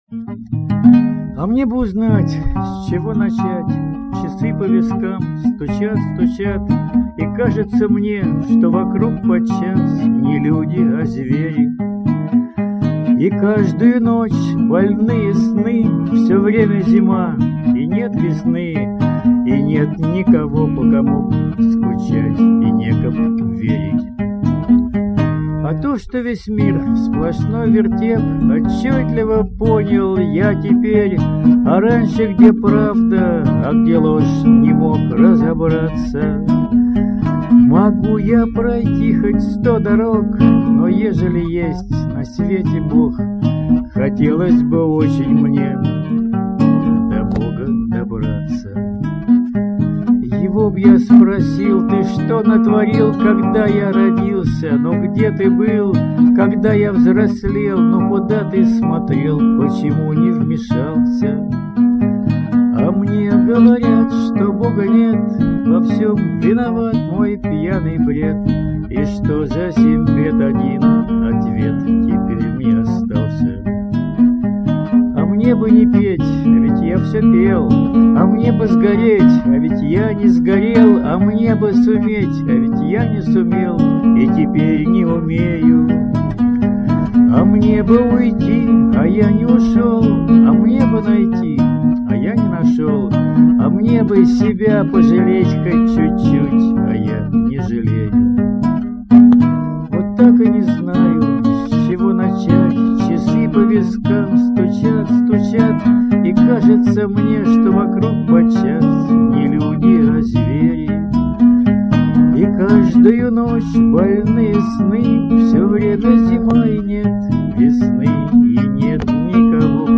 БАРДОВСКАЯ ПЕСНЯ...